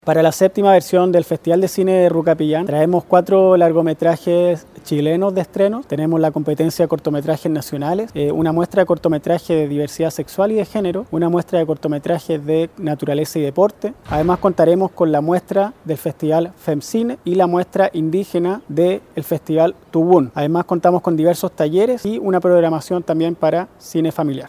Un colorido y formal lanzamiento tuvo hace algunos días la séptima versión del Festival de Cine Rukapillan Pucón 2025, el que vivió esta jornada estelar y “en sociedad” en la Universidad de La Frontera (UFRO) en Temuco, en donde fueron convocados autoridades y prensa local, adelantando el nutrido programa de este certamen fijado entre el 26 y 30 de noviembre próximo en el campus Pucón de la entidad educativa regional.